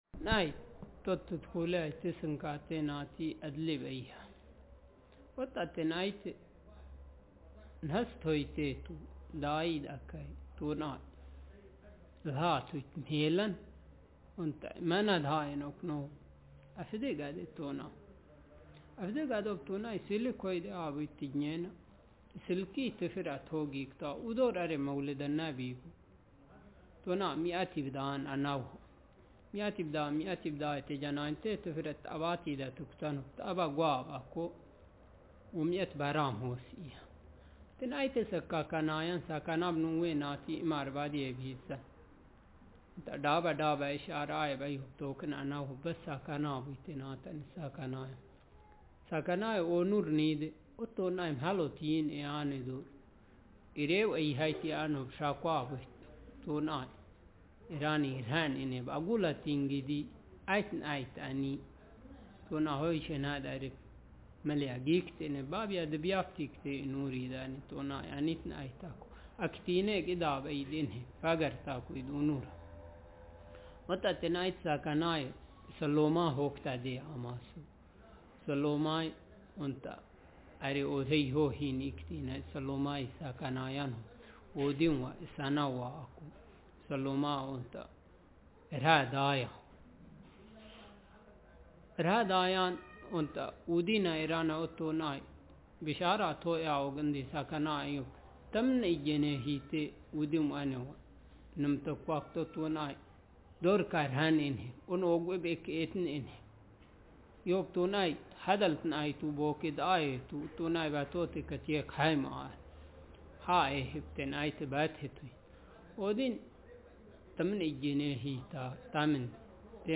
Speaker age35
Speaker sexm
Text genrepersonal narrative